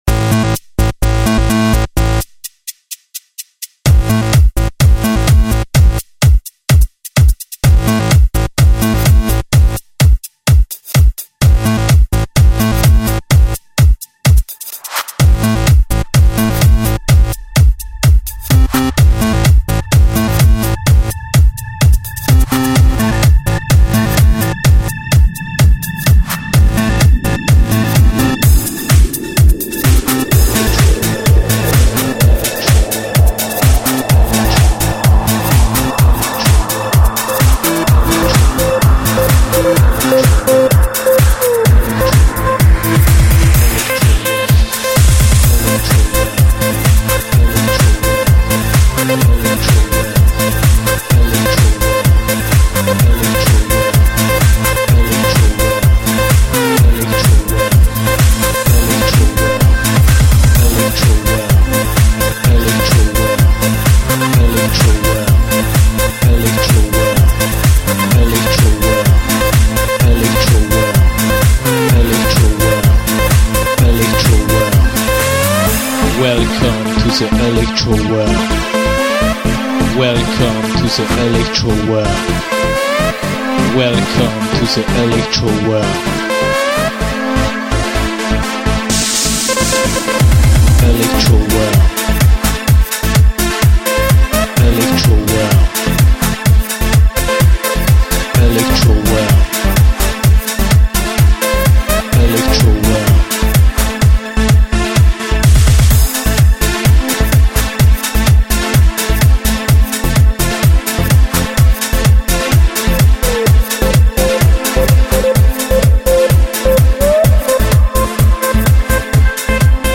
Ce morceau est a la fois mi-electro mi-trance , difficile de lui donner un genre.
Début electro classique, j'aime bien le synth qui monte progressivement.
Sinon le vocal fait tripper, tu devrais peut être vocoder tout ça un peu non?